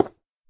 stone4.ogg